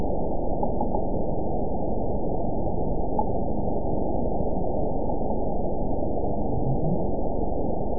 event 914679 date 10/18/22 time 21:33:19 GMT (2 years, 6 months ago) score 9.38 location TSS-AB03 detected by nrw target species NRW annotations +NRW Spectrogram: Frequency (kHz) vs. Time (s) audio not available .wav